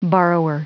Prononciation du mot borrower en anglais (fichier audio)
Prononciation du mot : borrower